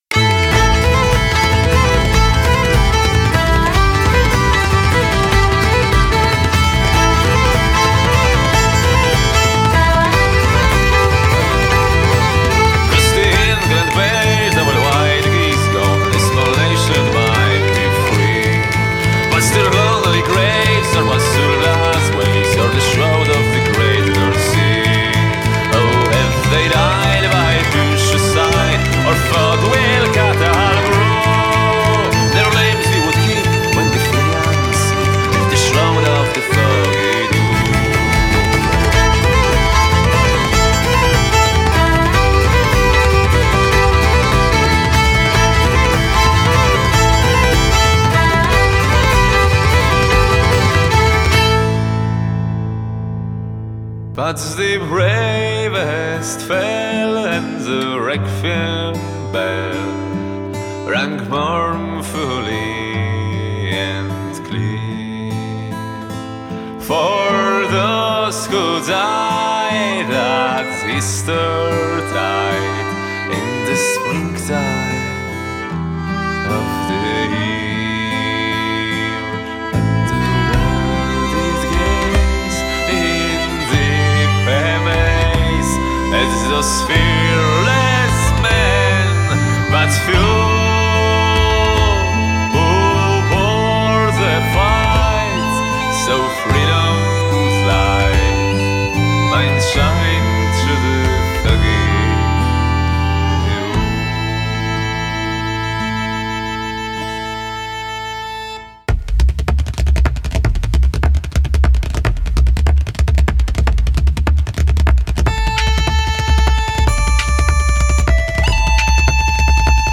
BPM75-150
Audio QualityPerfect (High Quality)
a Polish Celtic band
featuring male vocals.